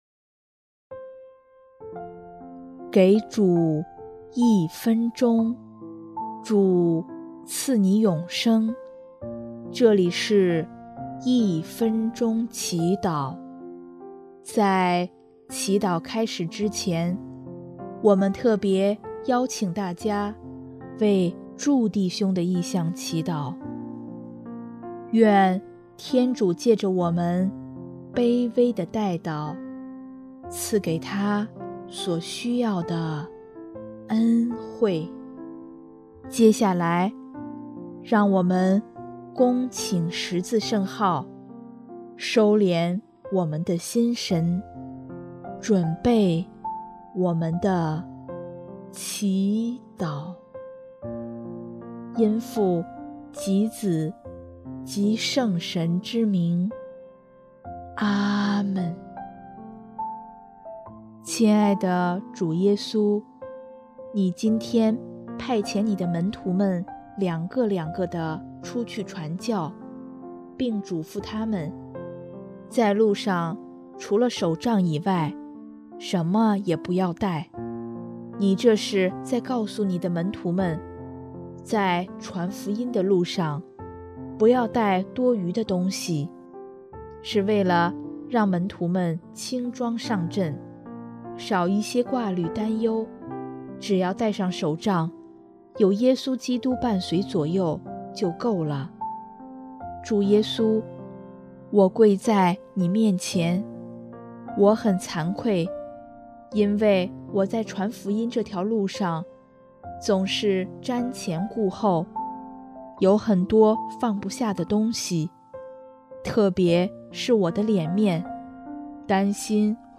【一分钟祈祷】|2月6日 求主赐我勇气为福音作证